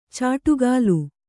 ♪ cāṭugālu